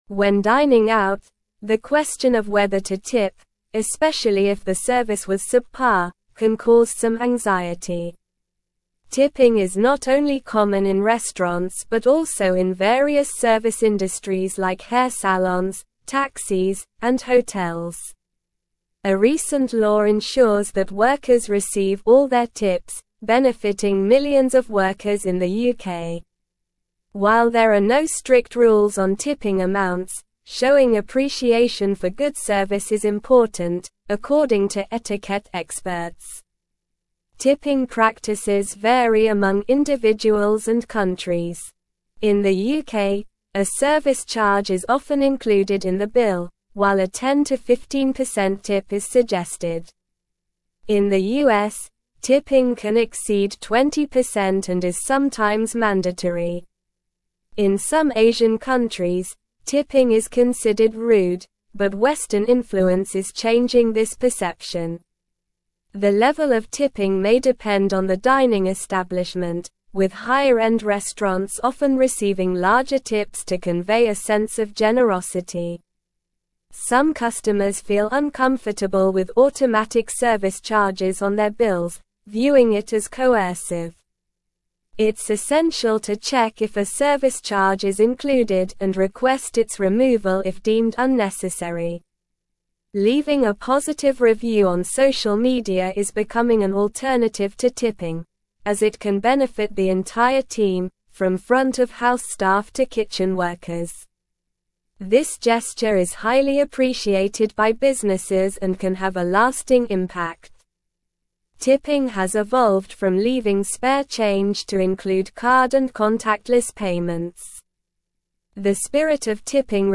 Slow
English-Newsroom-Advanced-SLOW-Reading-Navigating-Tipping-Etiquette-Acknowledging-Good-Service-Graciously.mp3